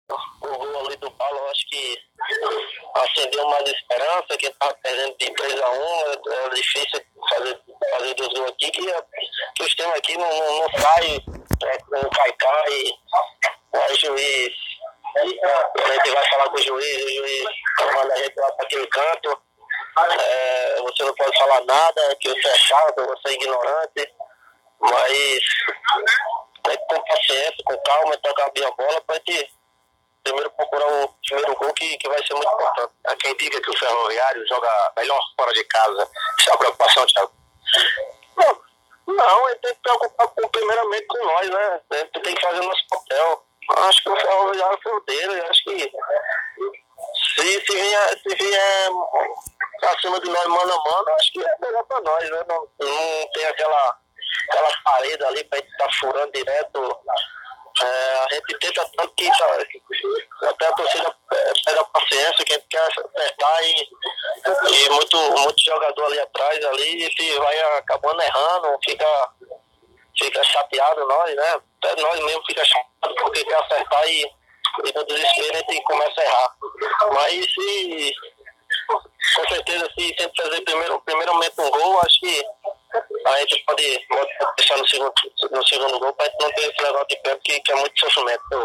pela quarta rodada do Campeonato Brasileiro da Série D. Ele recomenda que, a Raposa precisa fazer o primeiro gol para ter a tranquilidade de  buscar a vitória com dois gols de vantagem. Ouça entrevista